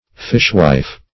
Fishwife \Fish"wife`\, n.